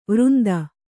♪ vřnda